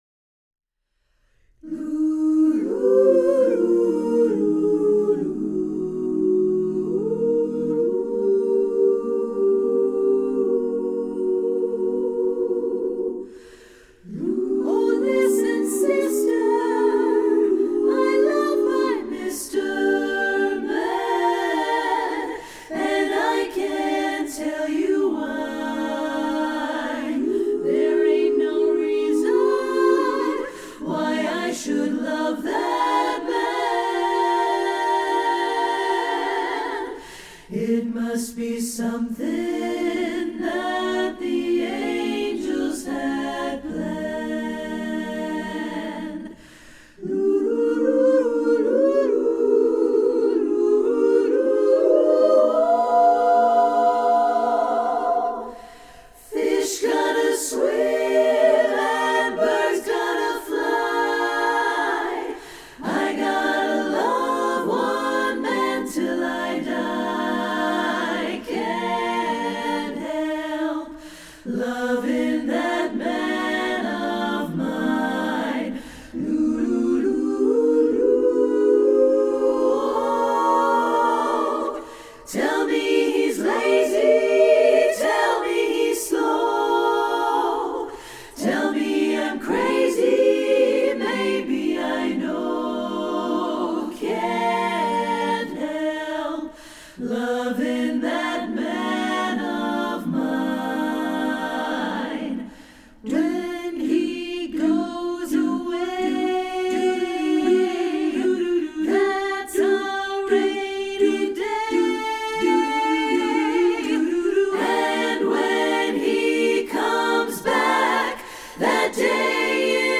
Voicing: SSAA a cappella